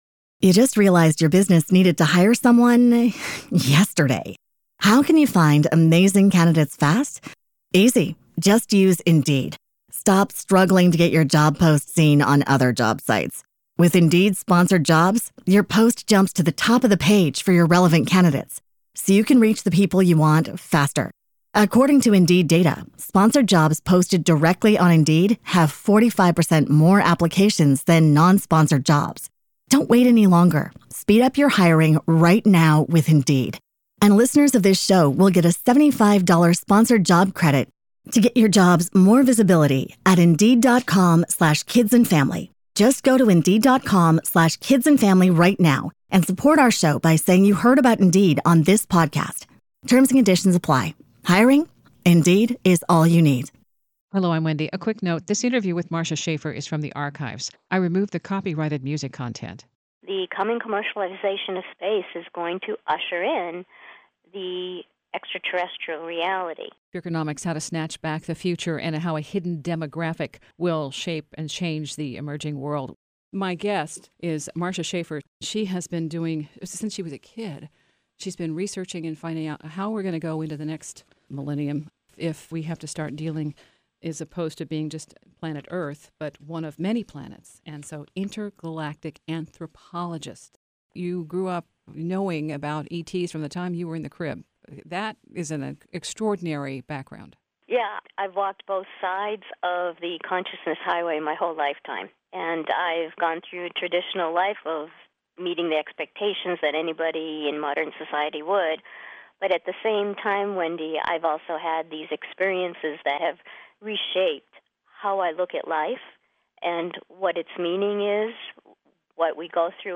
*Archived Interview Author Bio